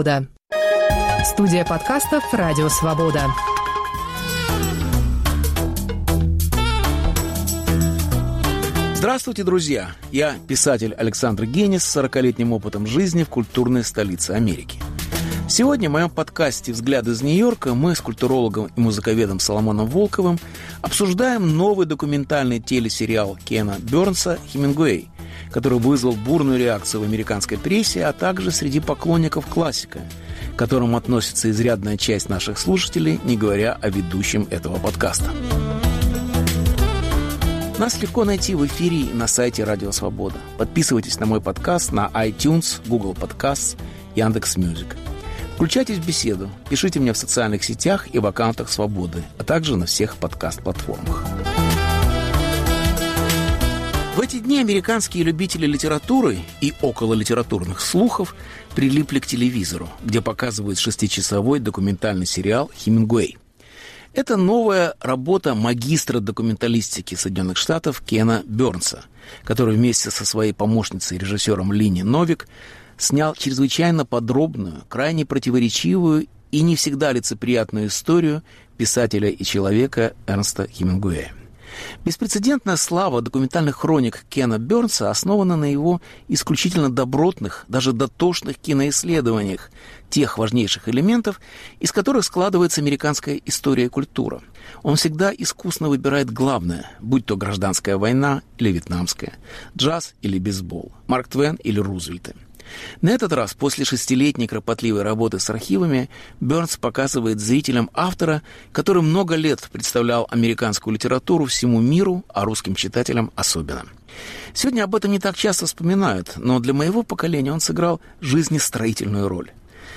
Беседа с Соломоном Волковым о сериале Кена Барнса